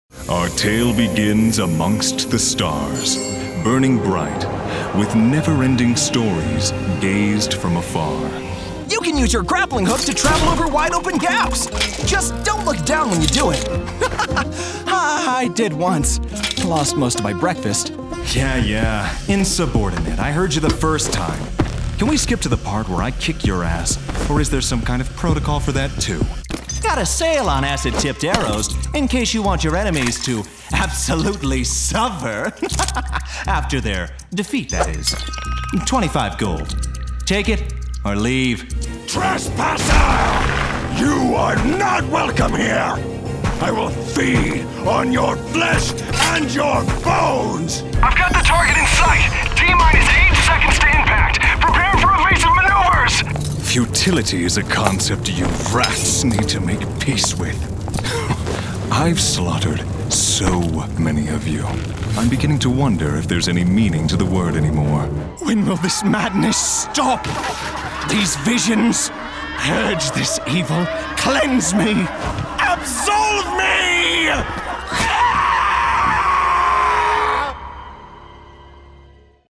Signal Chain: Neumann TLM 103 – PreSonus Studio Channel – PreSonus Audiobox iTwo – Adobe Audition;